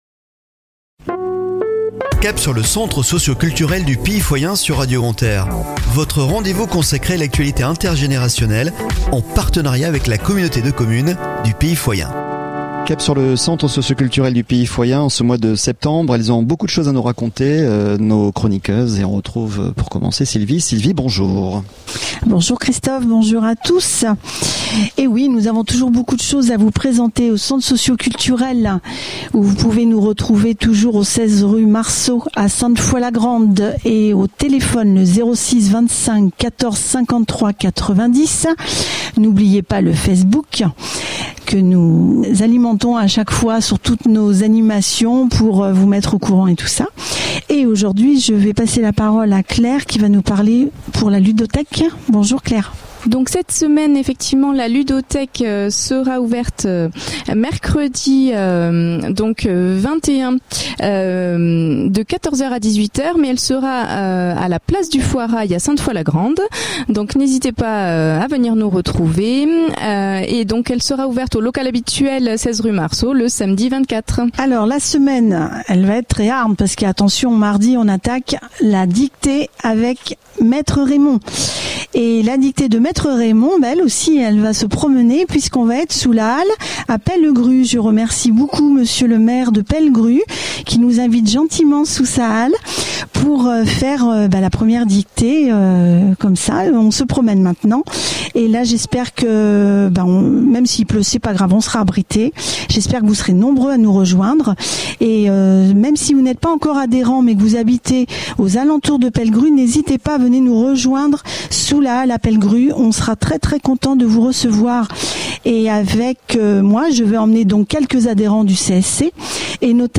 A écouter chaque Lundi à 09h30 et 17h30 , et Mercredi à 12h30 et 19h30 sur Radio Grand "R" en partenariat avec la Communauté de Communes du Pays Foyen